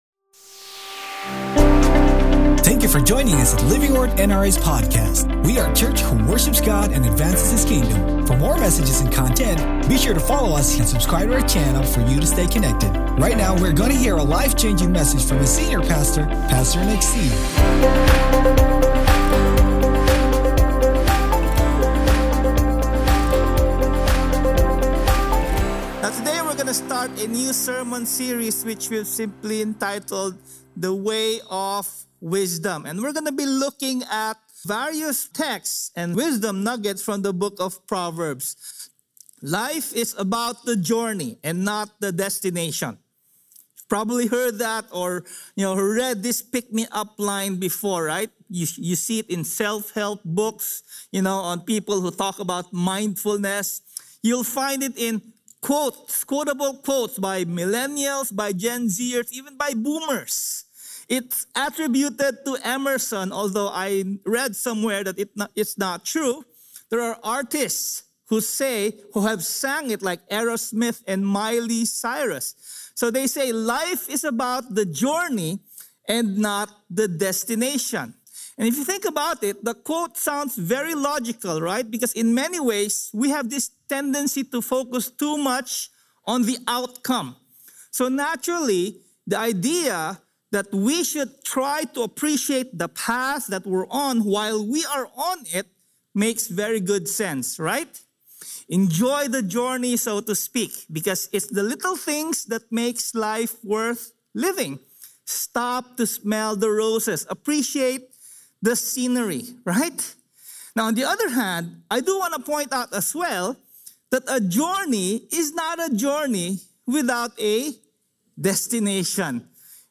Sermon Title: ROADS LEAD SOMEWHERE Scripture Text: VARIOUS TEXT FROM PROVERBS Sermon Notes: PROVERBS 4:18-27 NIV The path of the righteous is like the first gleam of dawn, shining ever brighter till the full light of day.